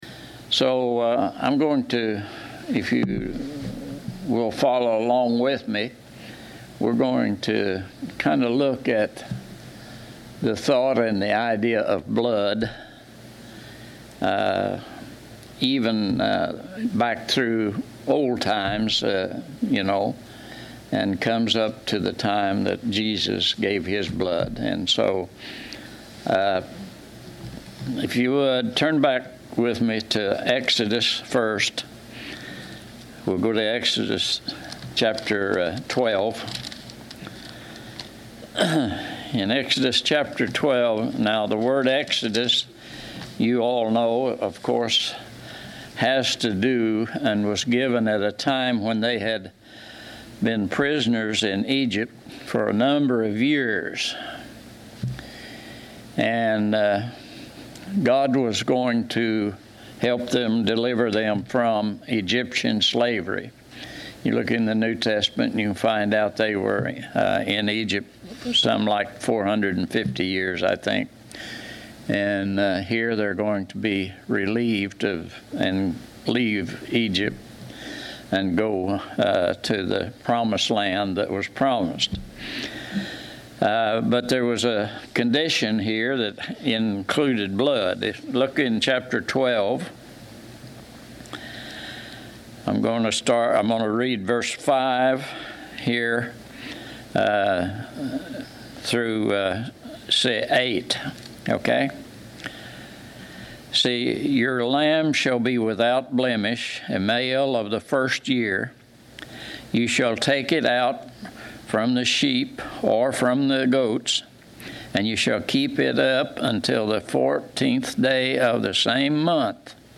Preacher
Service Type: Sunday 10:00 AM